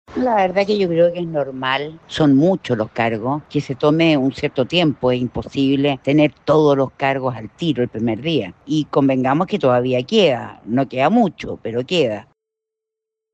Asimismo, la diputada del Partido Nacional Libertario, Gloria Naveillan, remarcó que una demora de este tipo es completamente normal, dado el número de cargos que deben definirse.